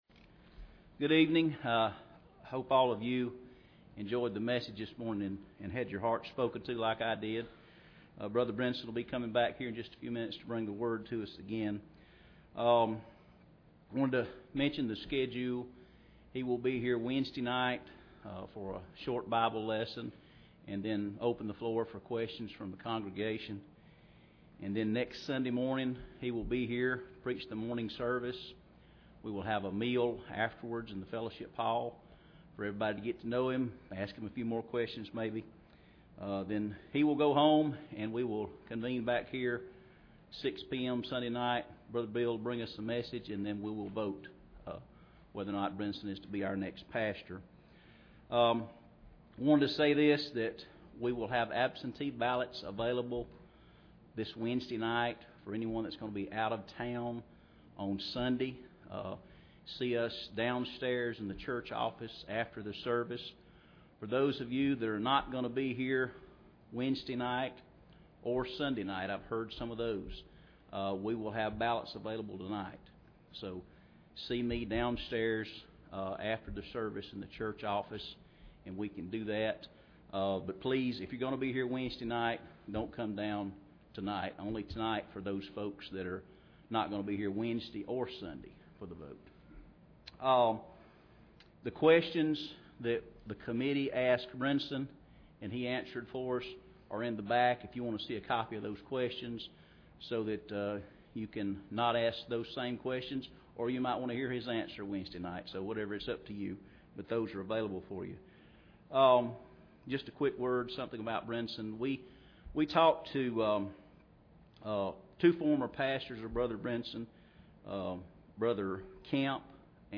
John 14:16-20 Service Type: Sunday Evening Bible Text